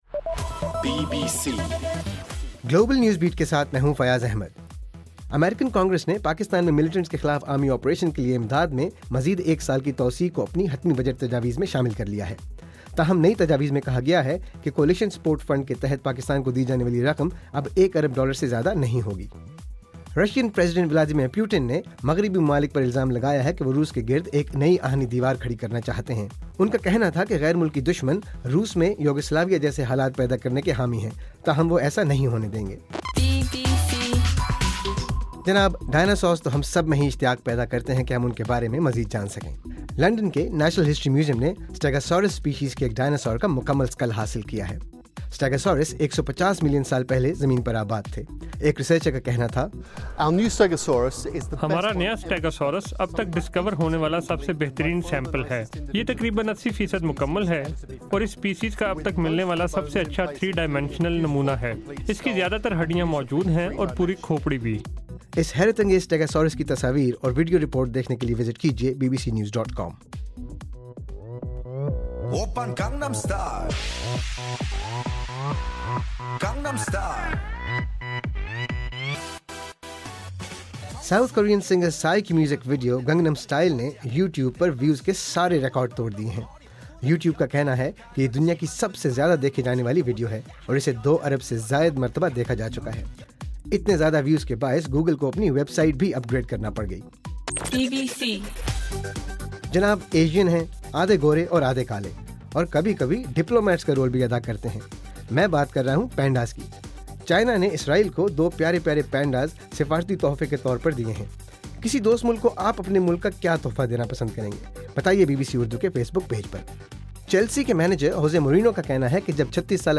دسمبر 4: رات 12 بجے کا گلوبل نیوز بیٹ بُلیٹن